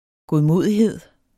Udtale [ goðˈmoˀðiˌheðˀ ]